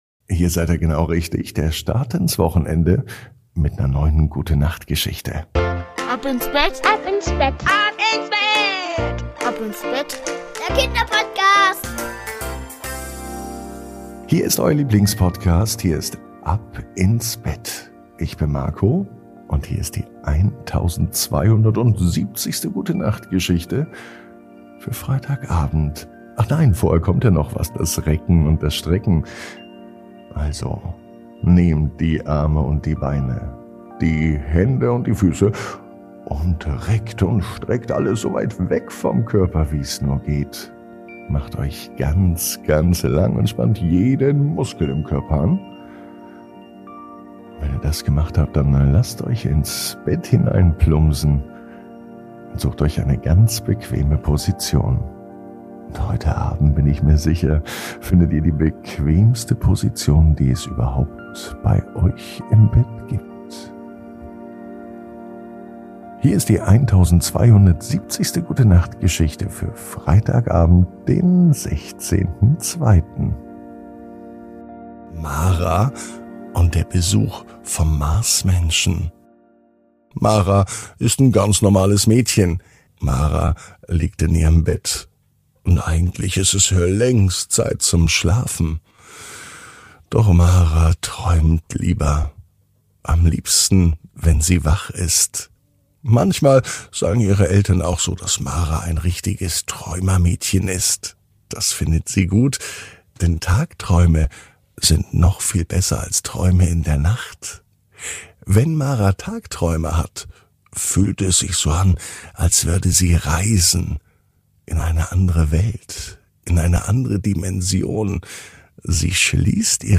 Die Gute Nacht Geschichte für Freitag